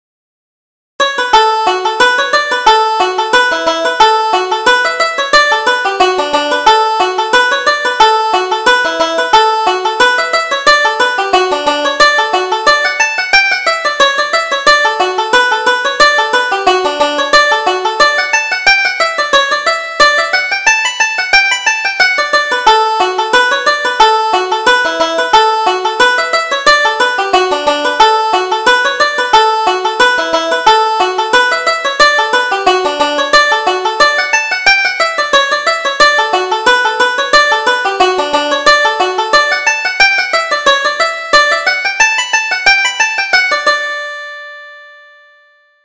Reel: The Miller's Maid